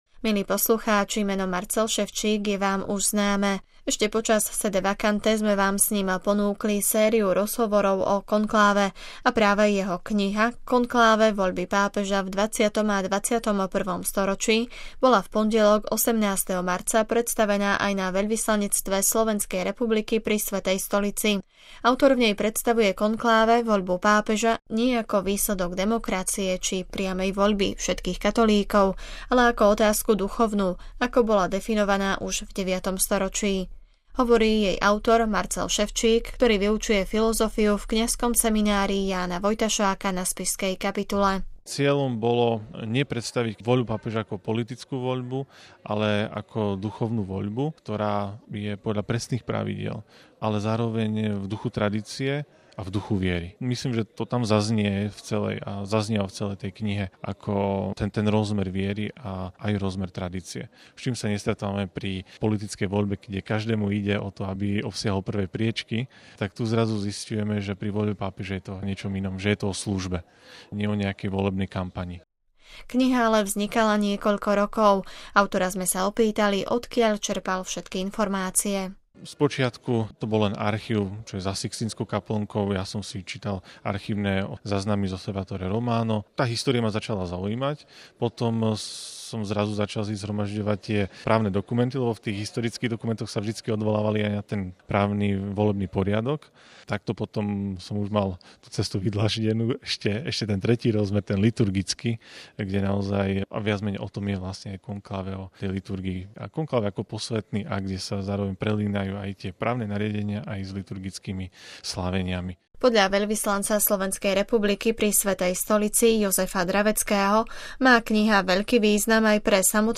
Reportáž z prezentácie knihy Konkláve – Voľby pápežov v 20. a 21. storočí
Reportáž z podujatia TU: RealAudio